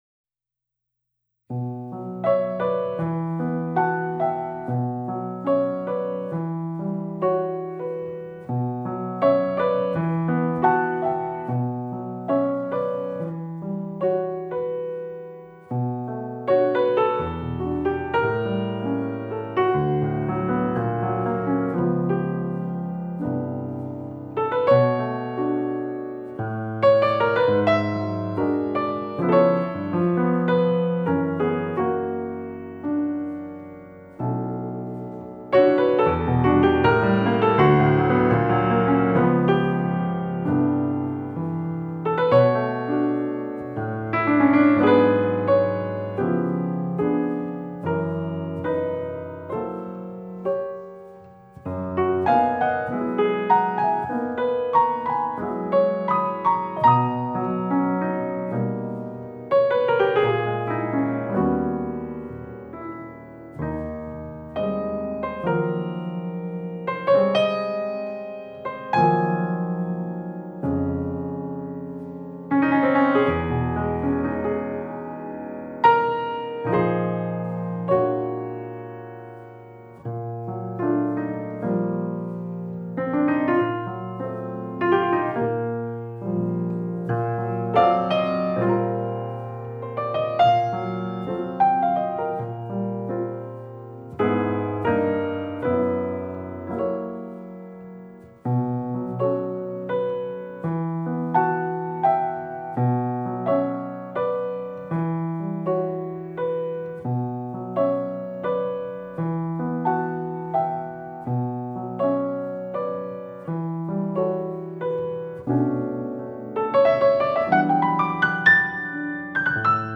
(proprie composizioni originali)